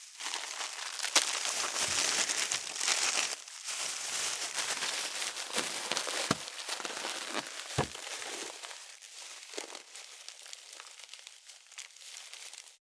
649ゴミ袋,スーパーの袋,袋,買い出しの音,ゴミ出しの音,袋を運ぶ音,